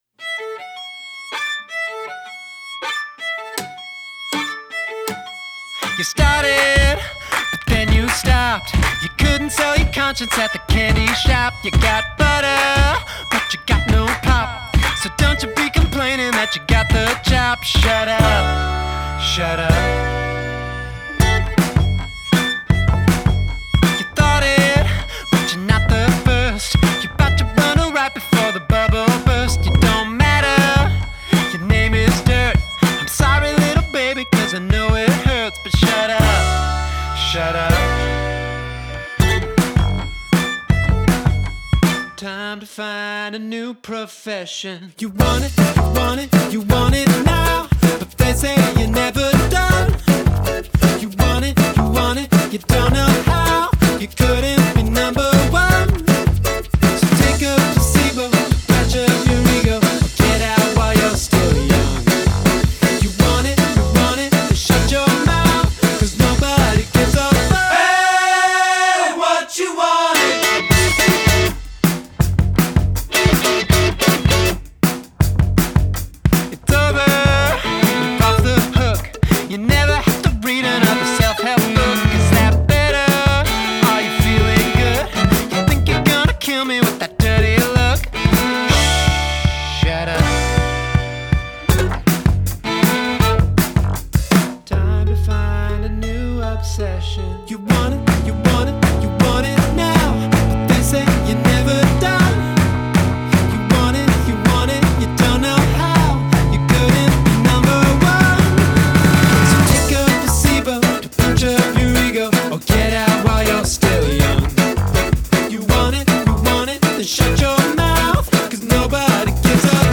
dans l'univers du rock et de la pop
quelques titres accrocheurs